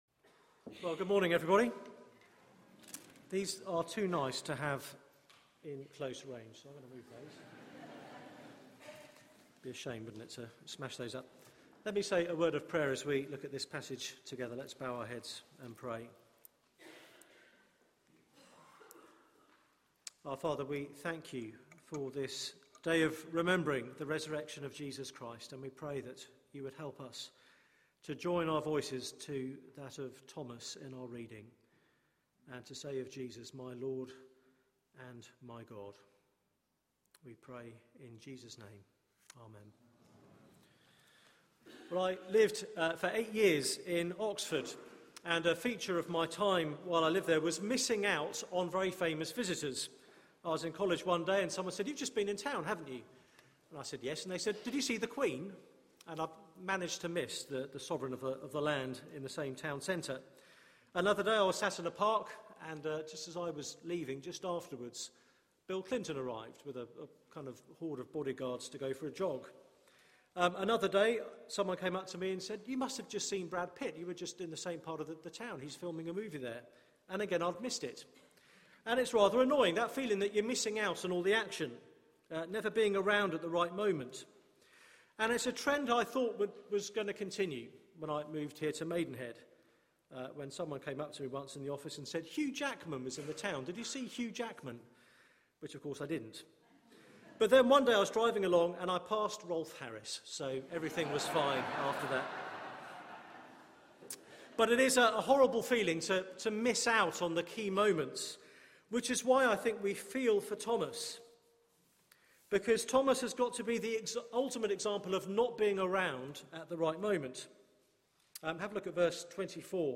Media for 9:15am Service on Sun 31st Mar 2013 09:15 Speaker
Easter 2013 Theme: Is Easter beyond belief? Sermon Search the media library There are recordings here going back several years.